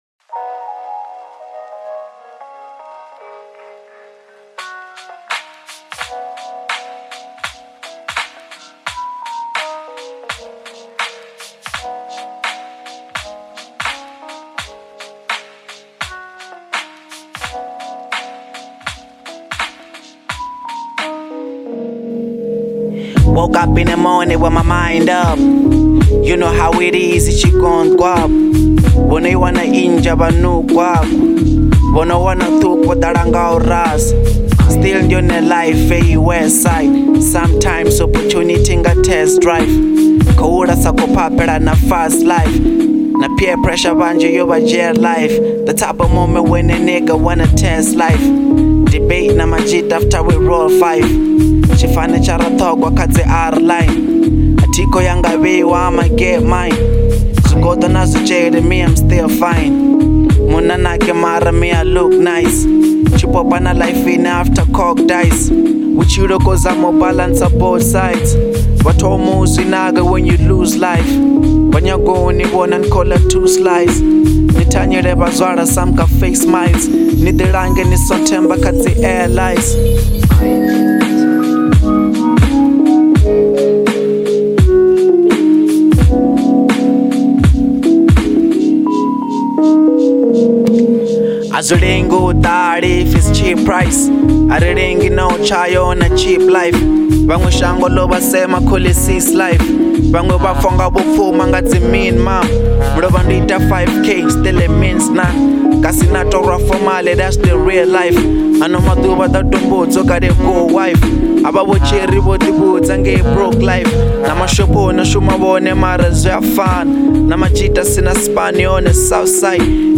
04:34 Genre : Venrap Size